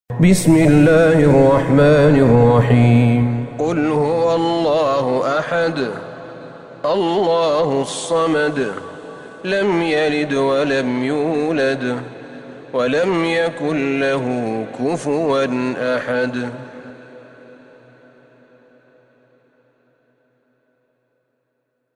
سورة الإخلاص Surat Al-Ikhlas > مصحف الشيخ أحمد بن طالب بن حميد من الحرم النبوي > المصحف - تلاوات الحرمين